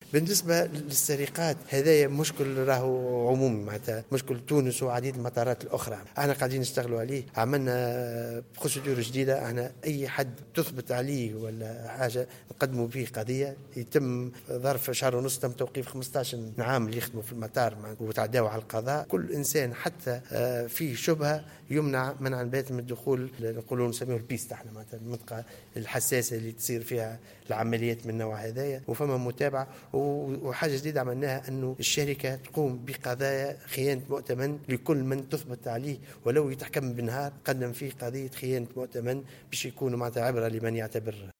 وأضاف في جلسة استماع بلجنة الفلاحة والأمن الغذائي والتجارة والخدمات، انه تم توجيه تهمة خيانة مؤتمن لهؤلاء وملاحقتهم قضائيا، مذكرا بإيقاف نحو 15 عاملا عن العمل في أقل من شهرين كما تم منع أعوان تحوم حولهم شبهة من دخول مدارج المطارات لشحن الحقائب.